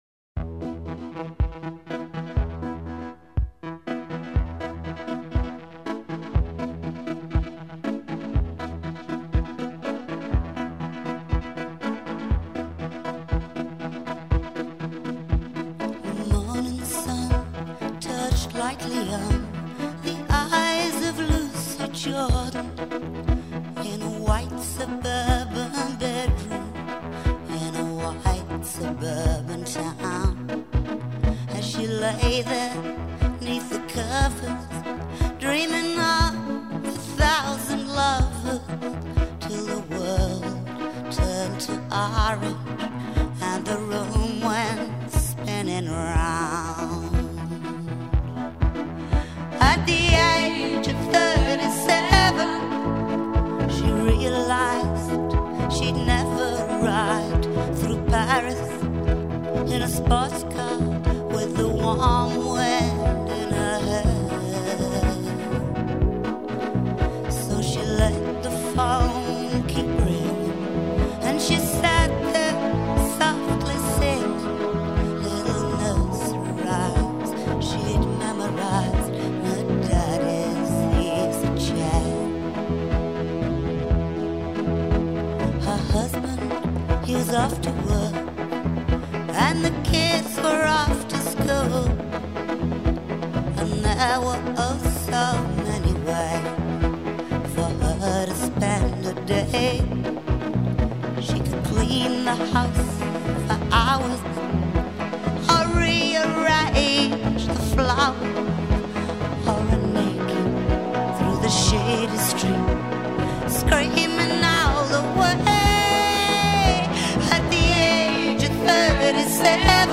sa voix rauque inimitable